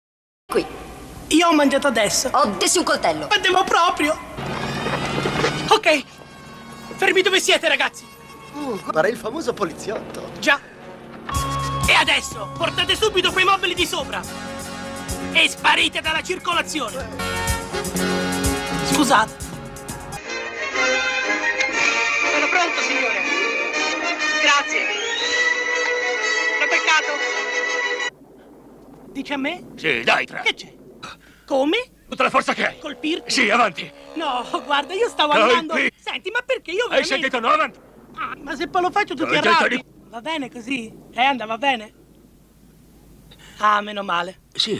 nel film "Scuola di polizia", in cui doppia Donovan Scott.